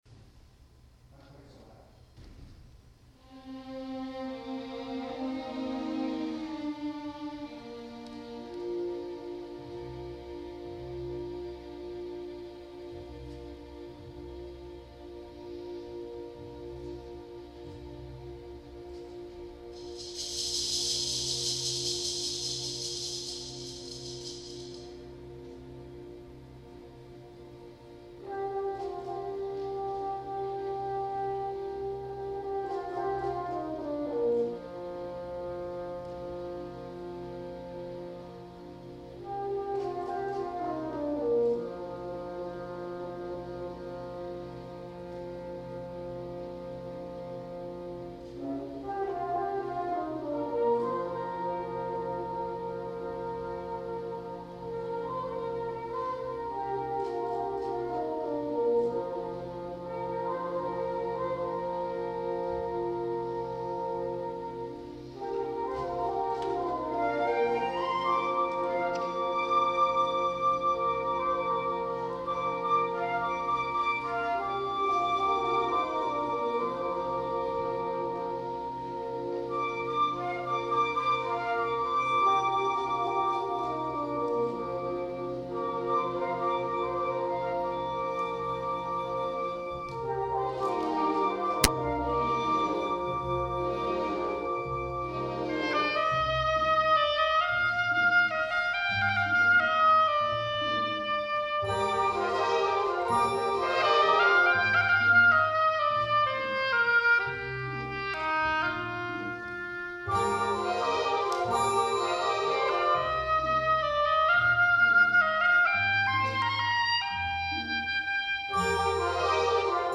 for Oboe and Chamber Orchestra (2003)